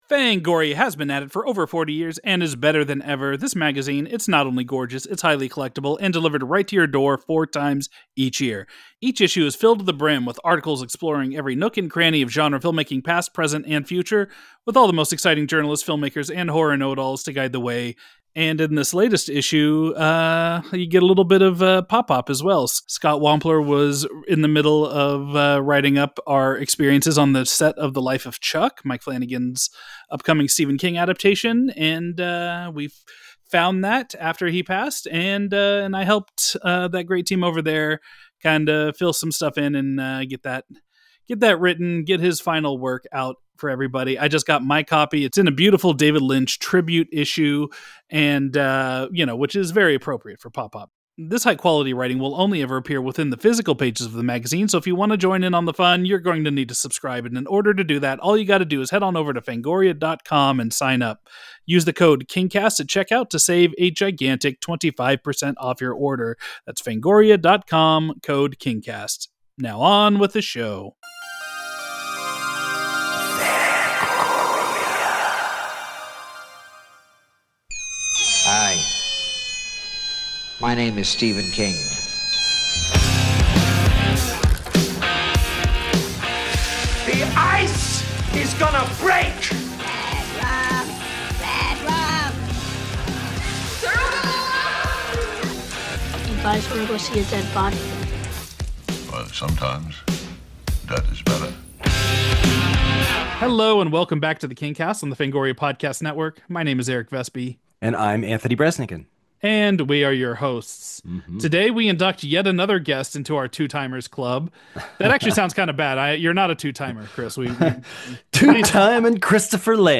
This wide-ranging conversation covers Chris's relationship with King's work, his famous father's influence in pointing him towards horror when he was a kid, his bizarre first read through of The Mist at age 12 (this story involves a real life death, by the way), his reaction to Frank Darabont's adaptation and so much more.